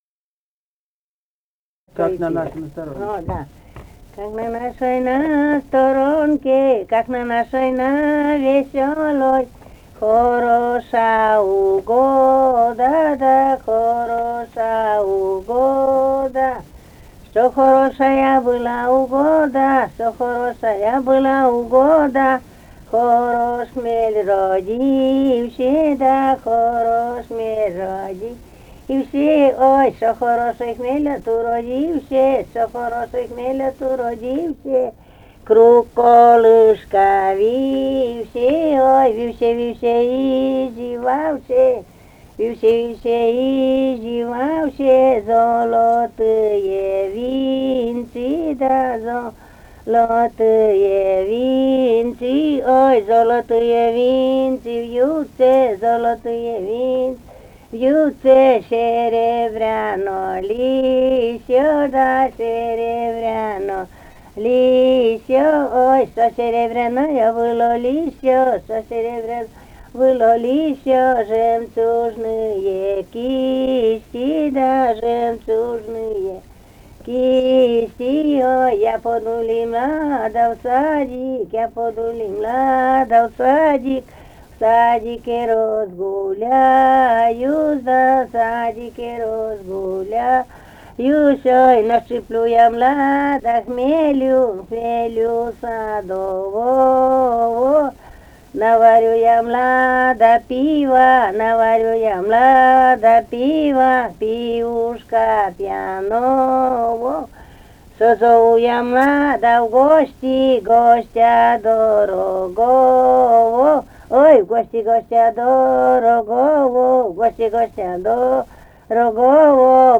«Как на нашей на сторонке» (хороводная).
Вологодская область, д. Марьино Марьинского с/с Вожегодского района, 1969 г. И1131-14